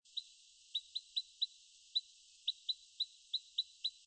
15-1阿里山栗背林鴝f1.mp3
栗背林鴝 Tarsiger johnstoniae
嘉義縣 阿里山 阿里山
路旁草地
雌鳥叫聲
Sennheiser 型號 ME 67